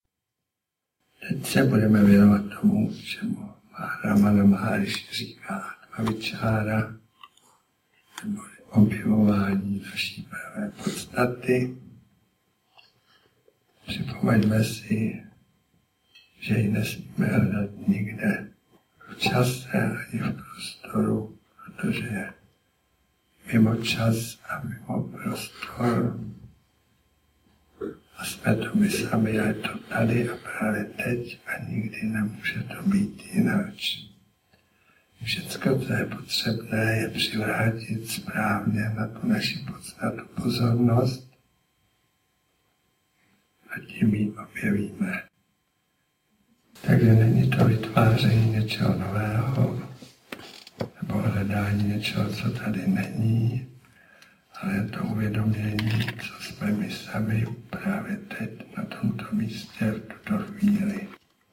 Audiokniha
Toto učení je začátkem i koncem všech duchovních cest k Bohu. Nejedná se o studiovou nahrávku.